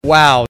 shotgunfire